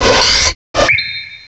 pokeemerald / sound / direct_sound_samples / cries / gallade.aif